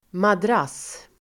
Ladda ner uttalet
Uttal: [madr'as:]